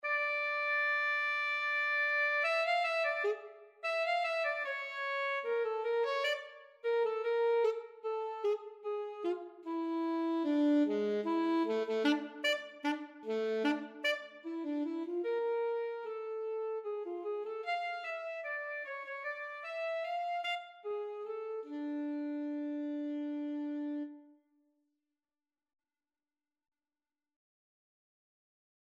East-european minor scale
alto-saxophone-audio.mp3